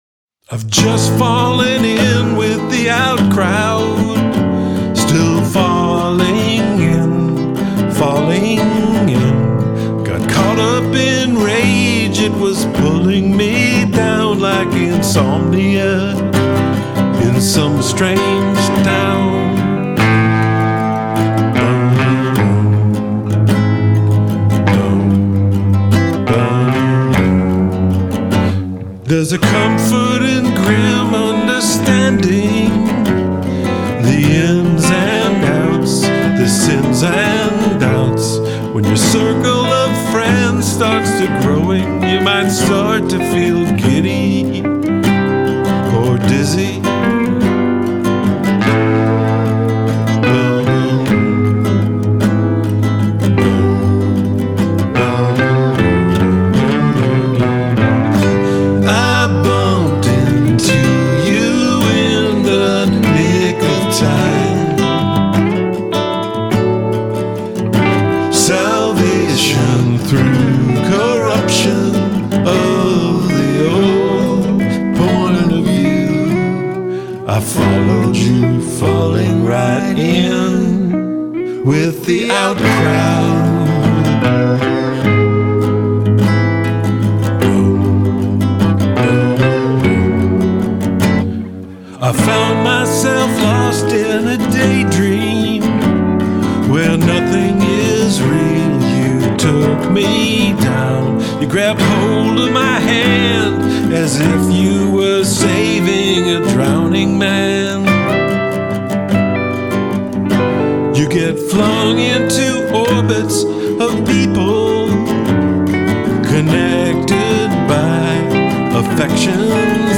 “faux folk” album